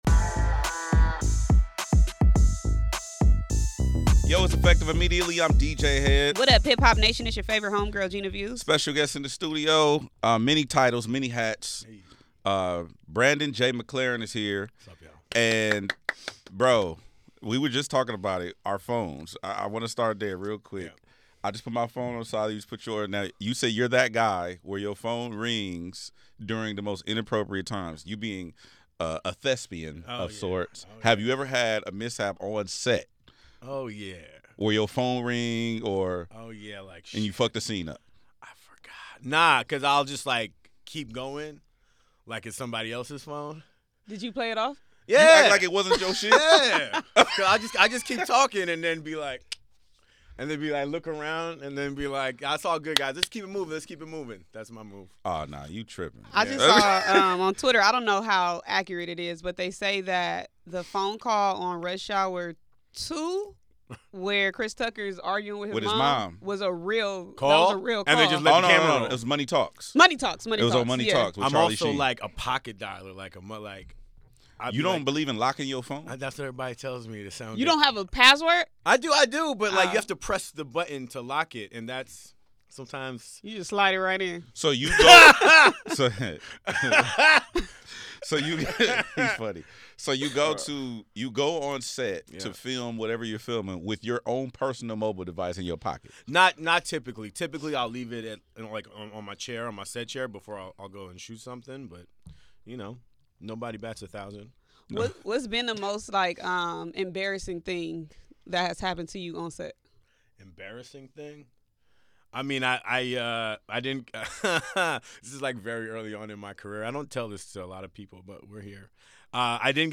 Effective Immediately is a nationally syndicated radio show and podcast that serves as the ultimate destination for cultural conversations, exclusive interviews, and relevant content.